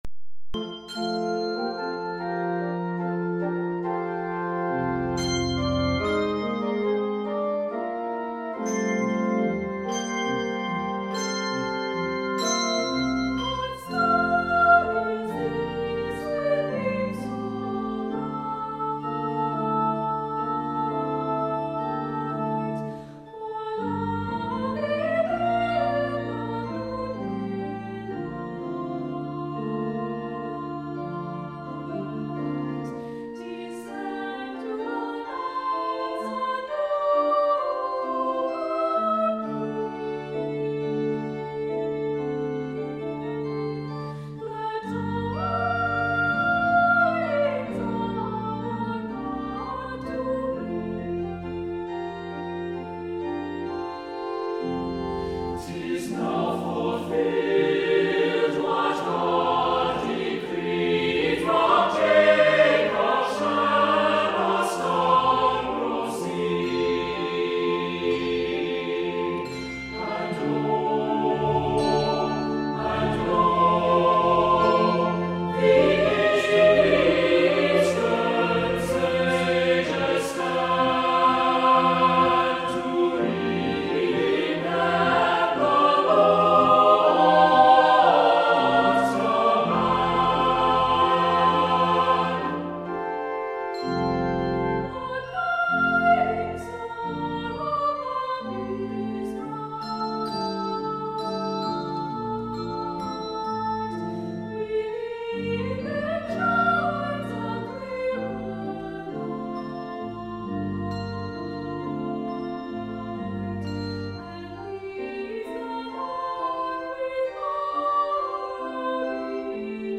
Voicing: SATB; optional Children's Choir